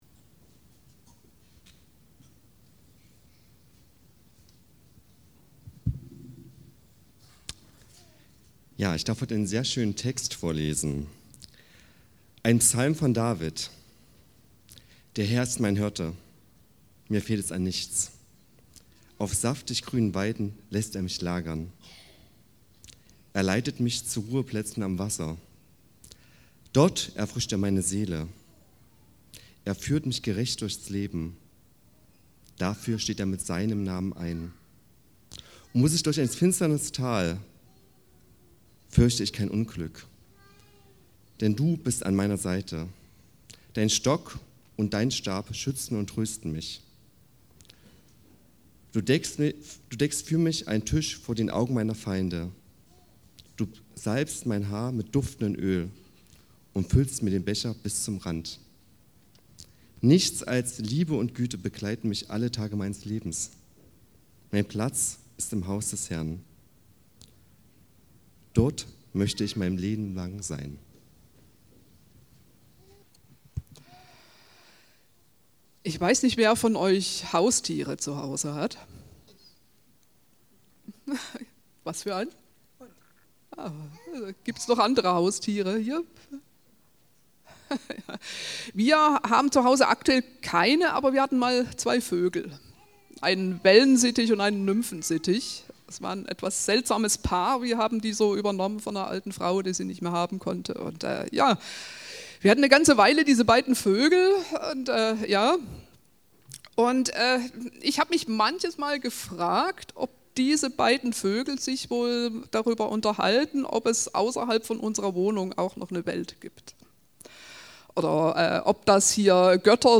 251207-predigt.mp3